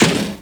Snare Drum 67-01.wav